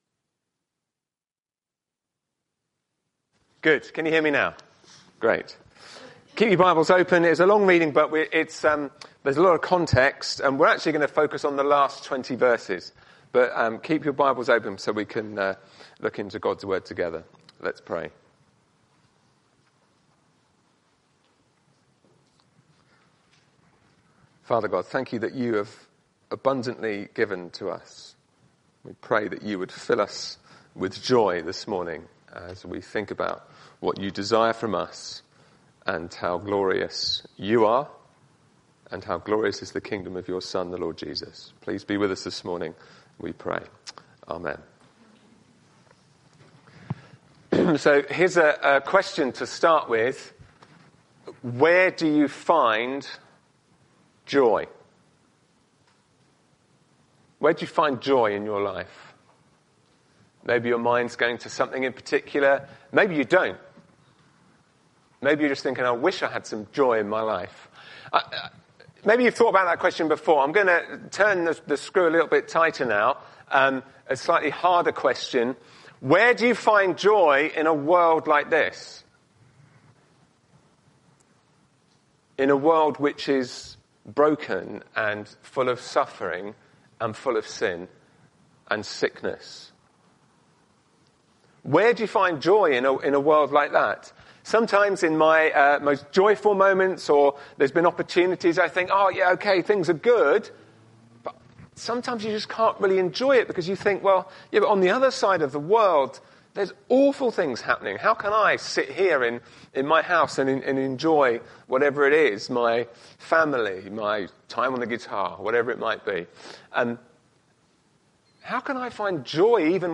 Sunday-Service-_-5th-Oct-2025.mp3